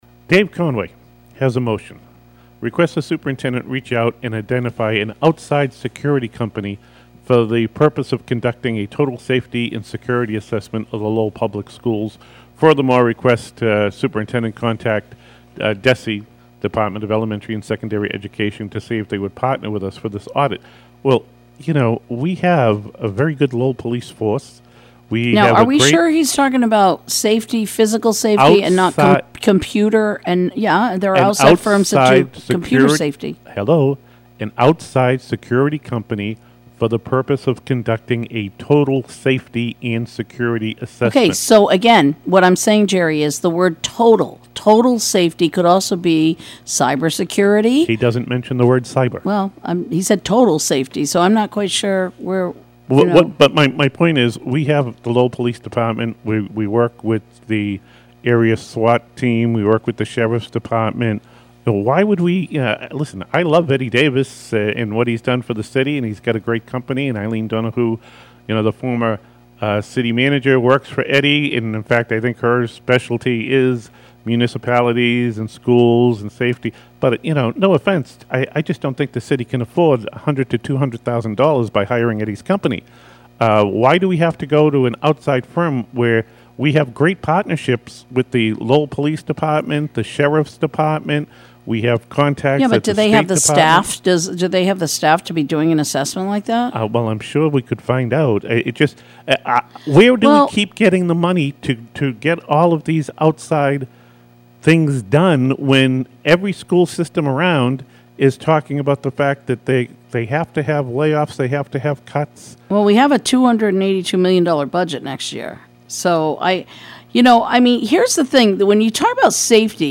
Last night, we brought you the story about an altercation at Lowell High School that became controversial fodder for local talk radio, and led to Lowell Mayor Dan Rourke issuing a statement.